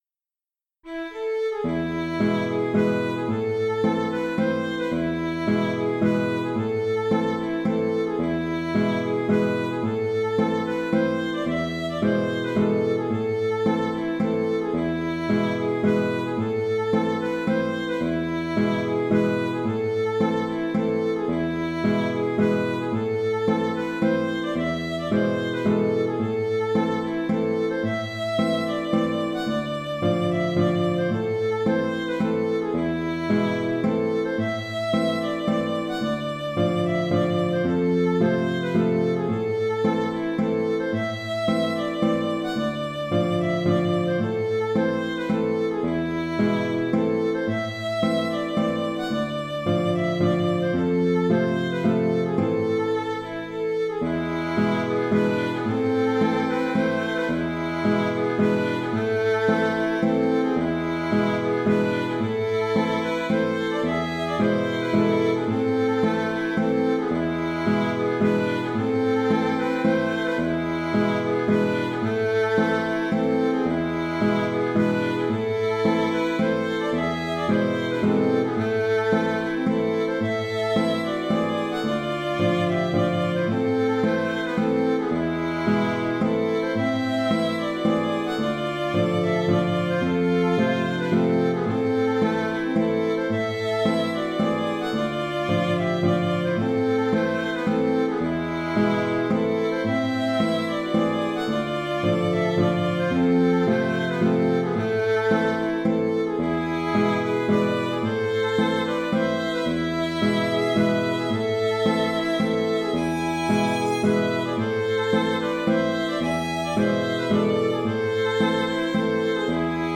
Mazurka suédoise (Mazurka) - Musique folk
Mais elle ne manque pas d'intérêt, avec une entrée (après la levée) sur l'accord de dominante septième (E7), ce qui n'est pas très courant en folk traditionnel. Et un clin d'œil à la gamme dorienne (fa#) dans la partie B. J'ai fait deux contrechants à utiliser au choix qu'on peut écouter successivement dans le fichier mp3.